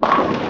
bowlingimpact.ogg